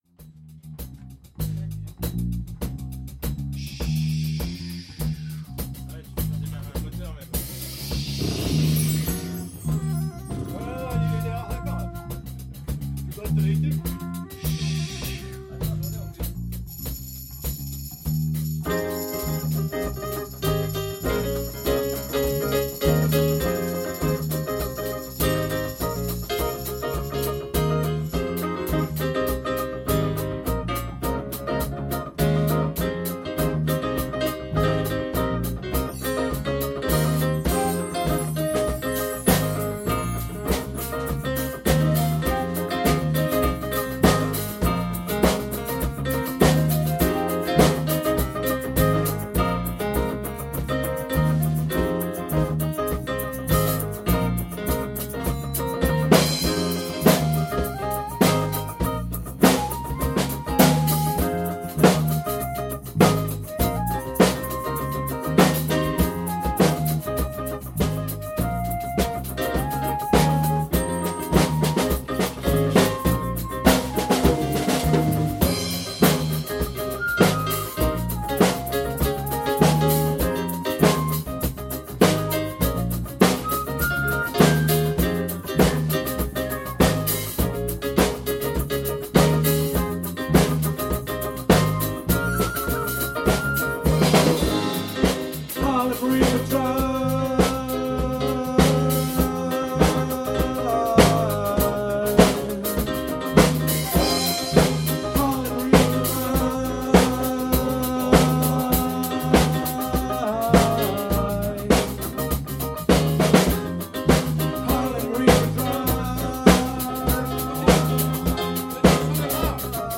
TEMPO: 101
Morceau en Fa mineur (gamme de Re Lab bemol, 4 bemoles)
Solo Flute